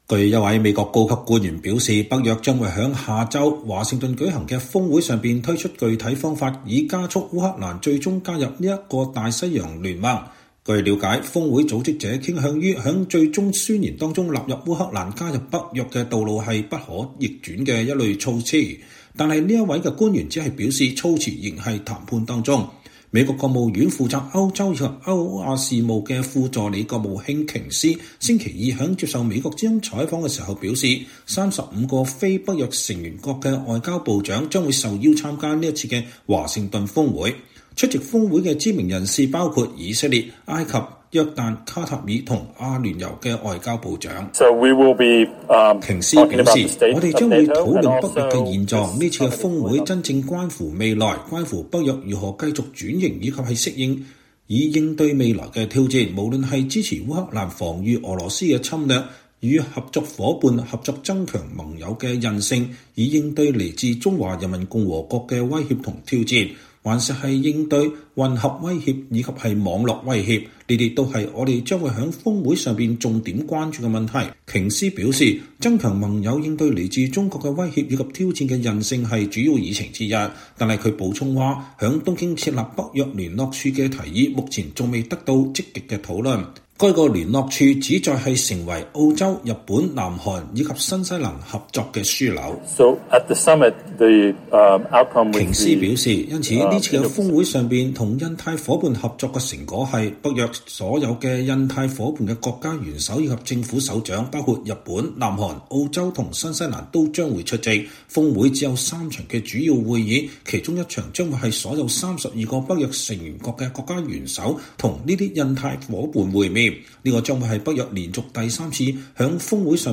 VOA專訪美國務院官員:北約峰會將公佈烏克蘭入盟具體步驟